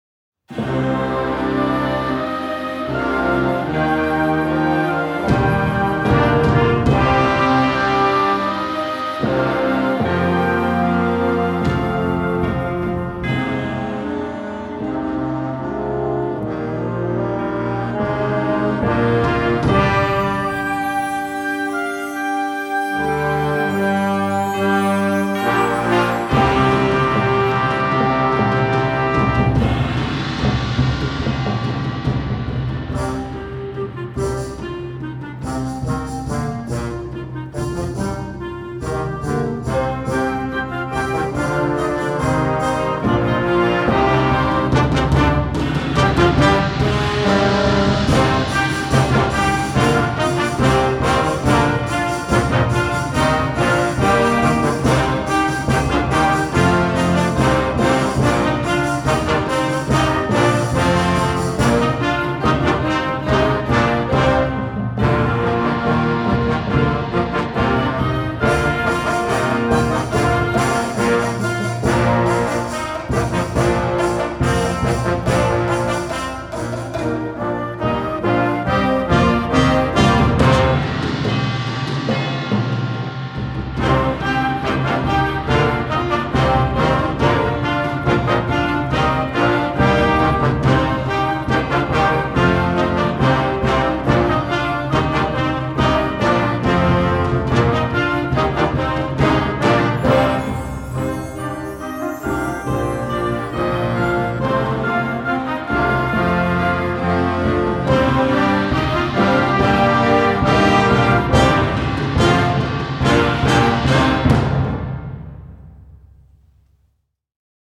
Gattung: Konzertwerk für Jugendblasorchester
Besetzung: Blasorchester
Positiv energiegeladen und sehr zugänglich!